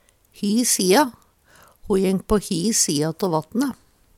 hi sia - Numedalsmål (en-US)